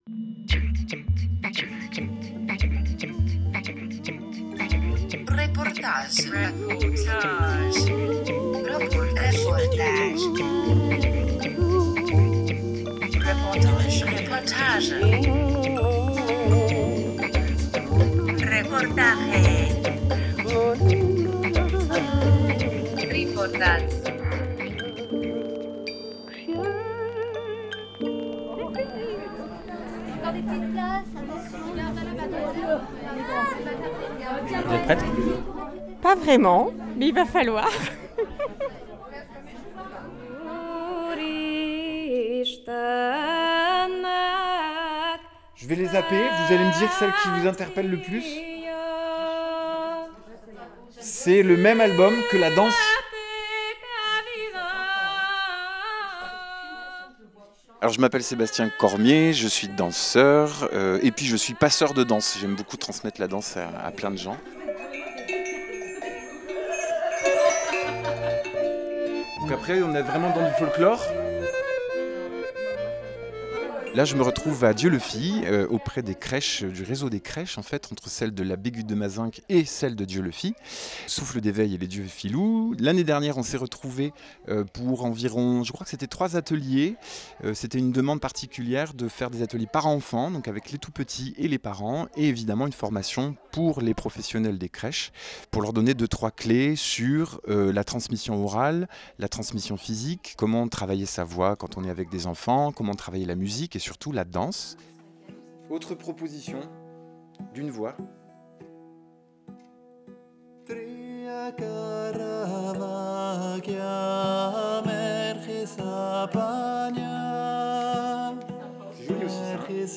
23 mars 2020 16:51 | culture, Interview, reportage, territoire